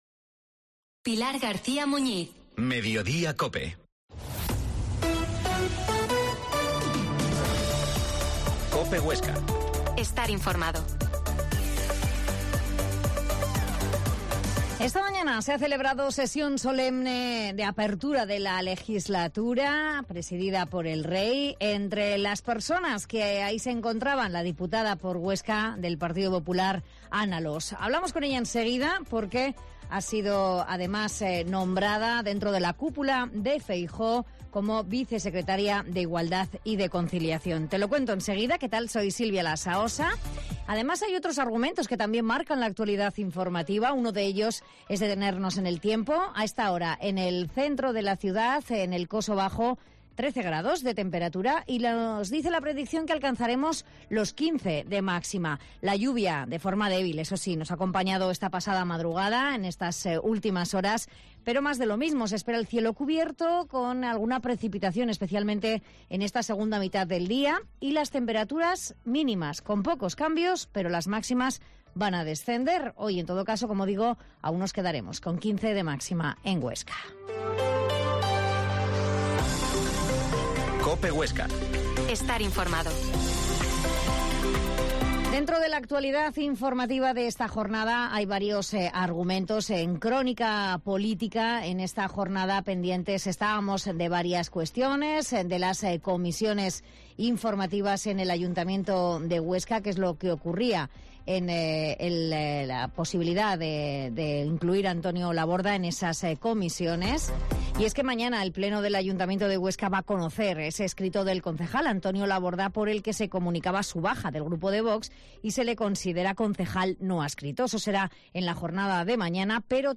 Mediodia en COPE Huesca 13.50 Entrevista a la diputada oscense Ana Alós